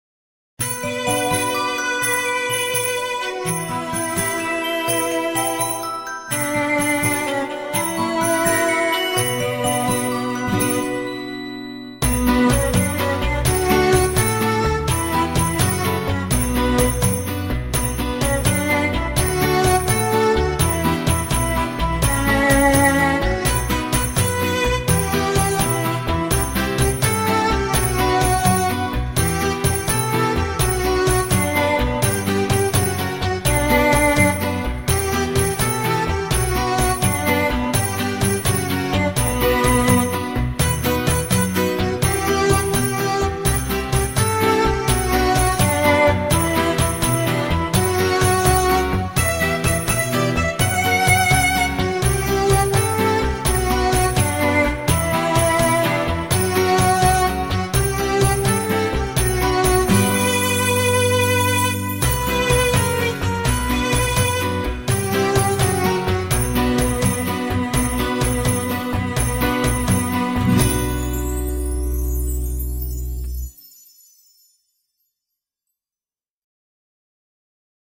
Колыбельная